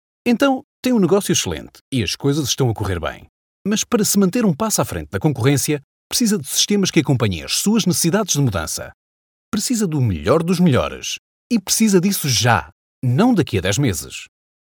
Natural, Playful, Friendly, Warm, Corporate
Corporate